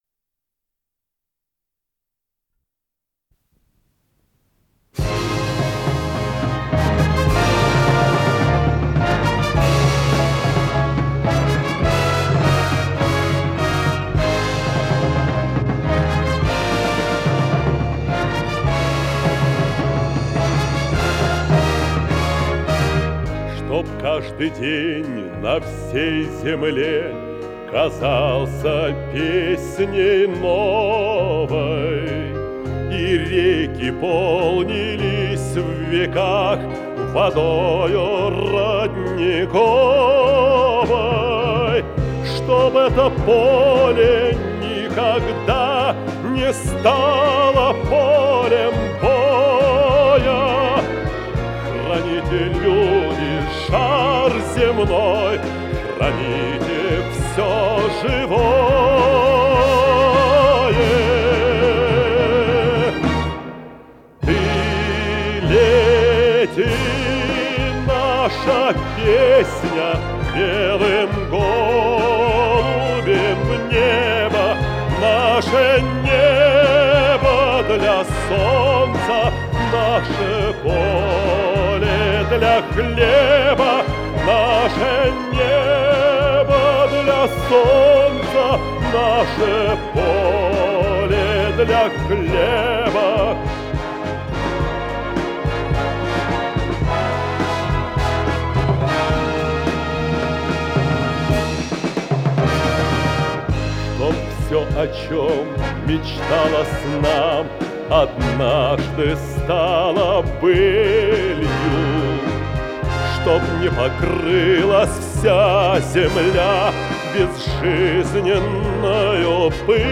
пение, баритон
ВариантДубль моно